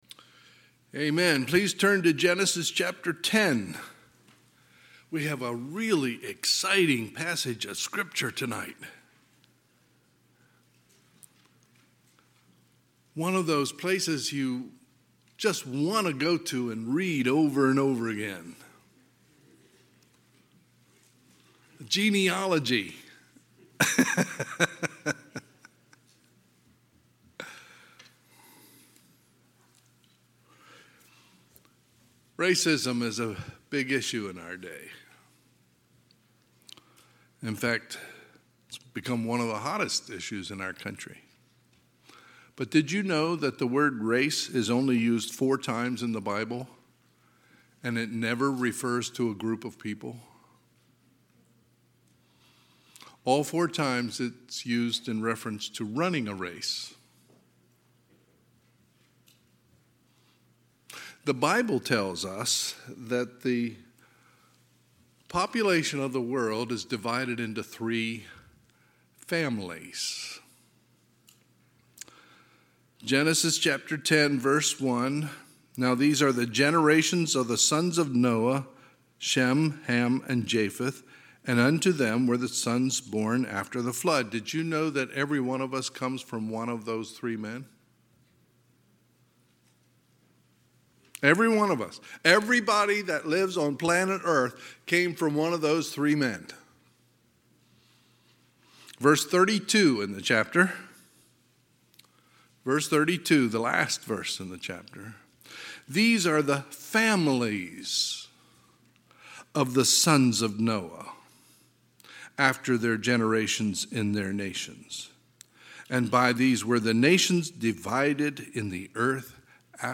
Sunday, March 27, 2022 – Sunday PM
Sermons